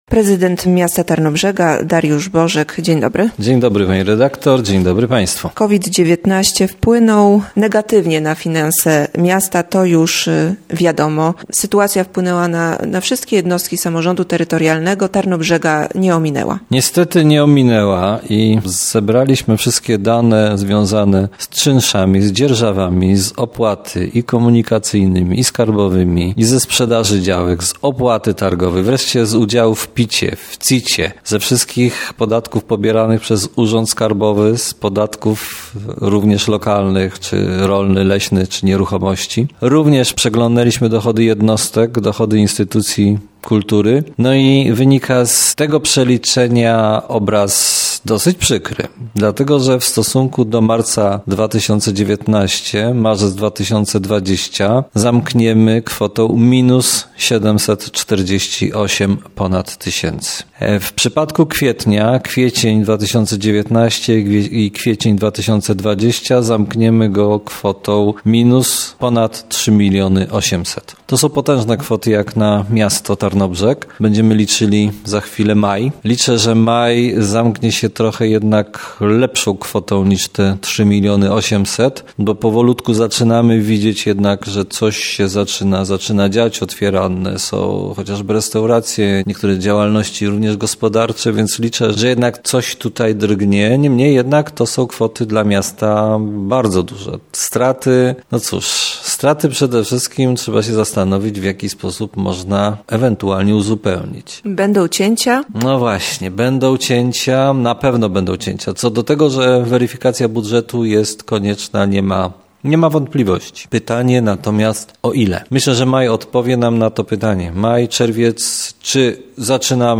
O wpływie pandemii na finanse Tarnobrzega. Rozmowa z prezydentem miasta, Dariuszem Bożkiem.